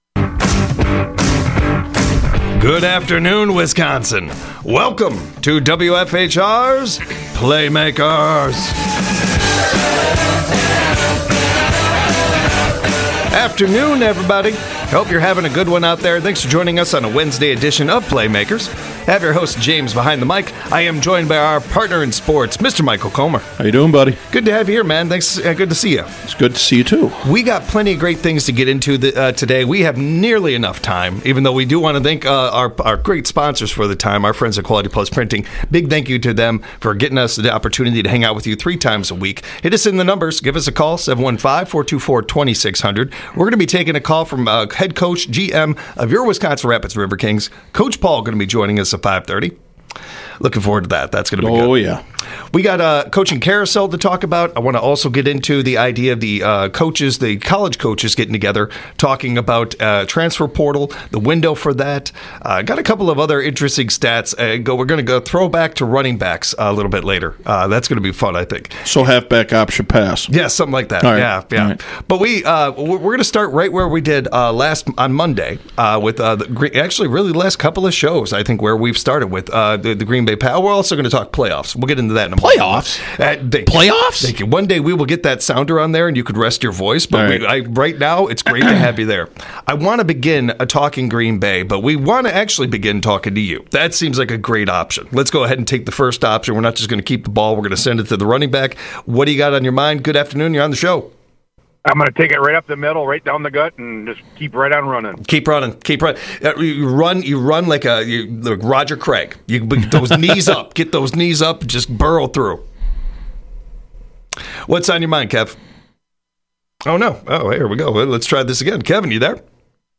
They've got a jam packed Wednesday night. They got busy talking Green Bay toughness, and The NFL coaching carousel.